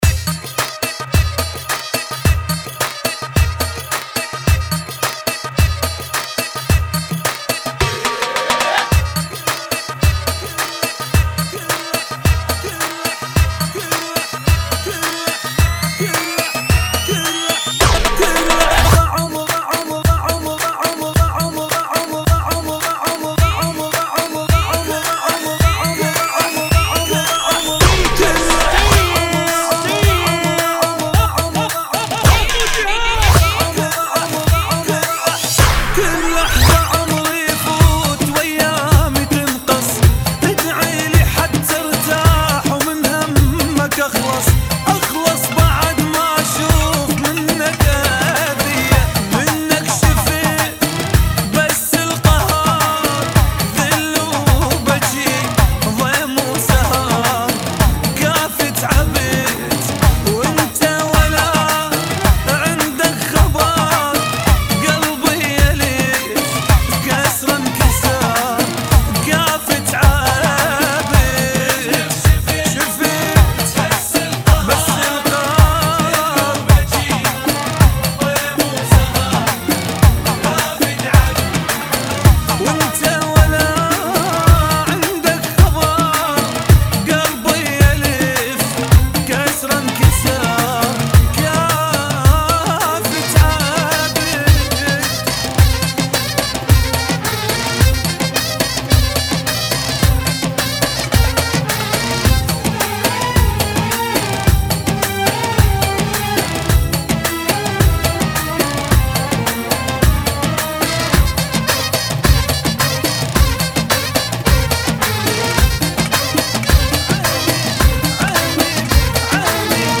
108 Bpm